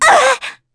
Pansirone-Vox_Damage_kr_03.wav